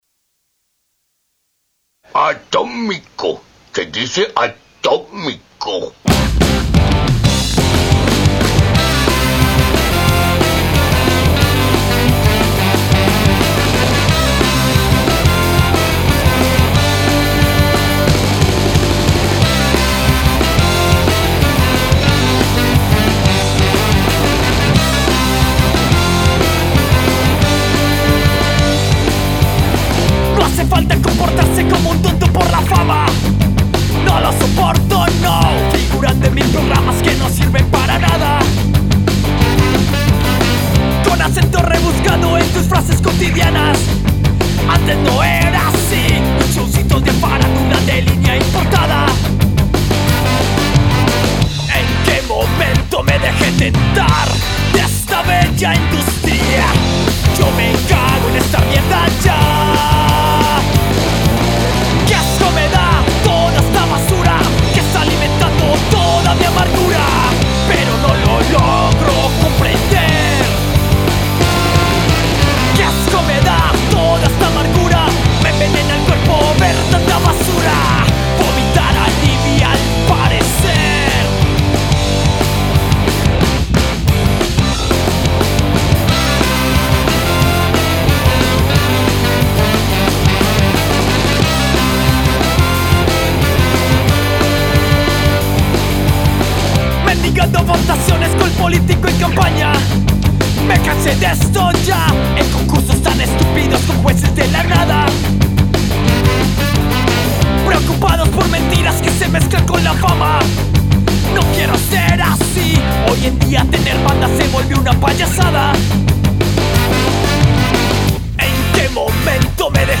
Skacore